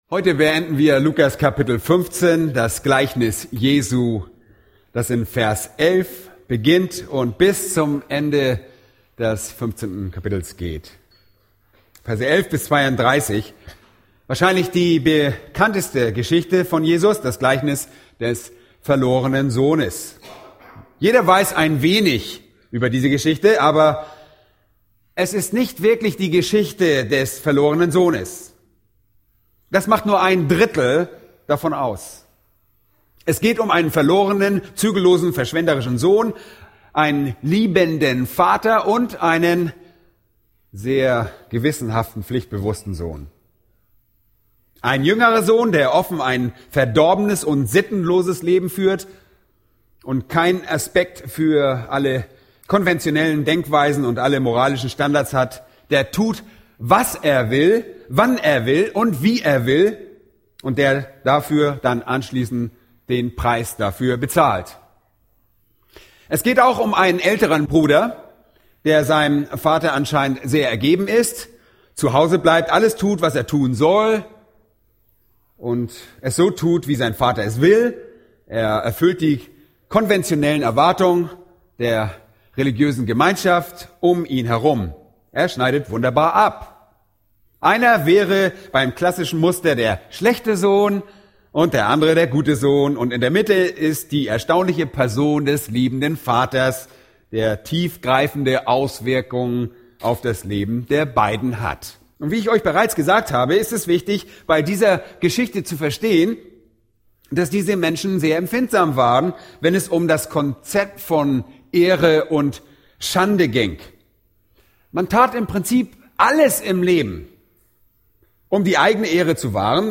Eine predigt aus der serie "Weitere Predigten."